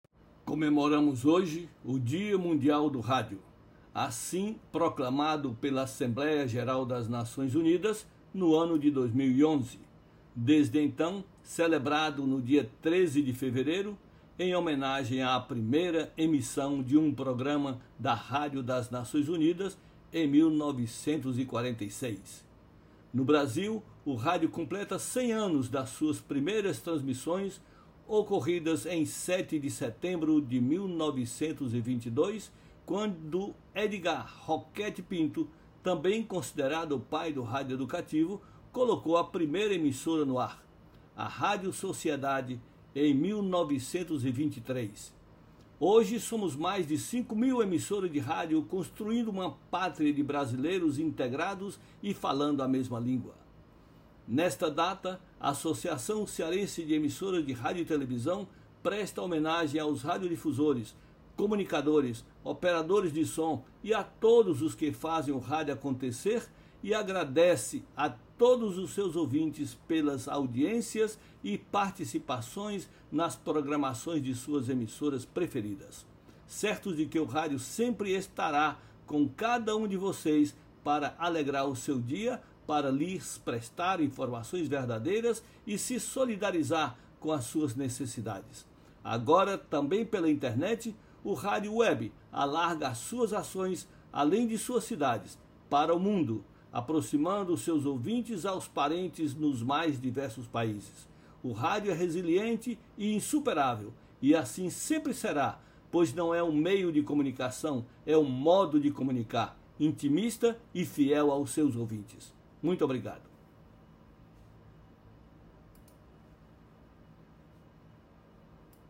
Pronunciamento-mp3.mp3